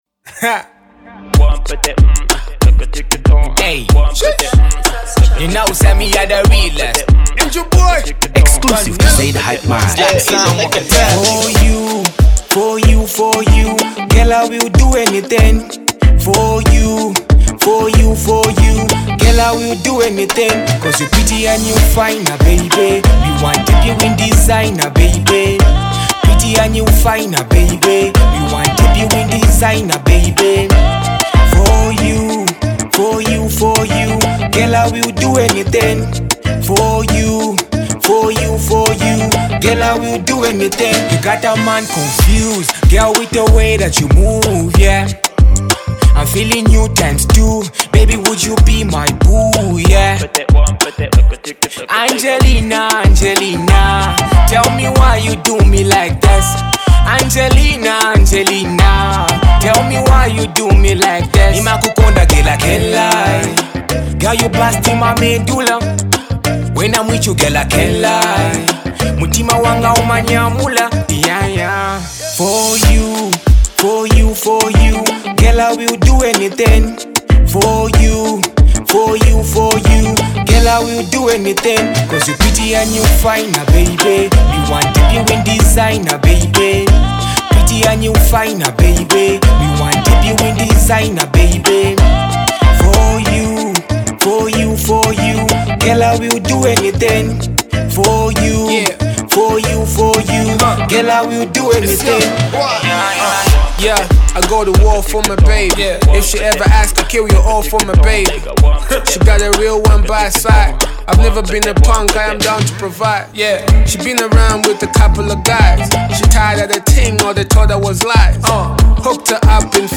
afro pop
The club banger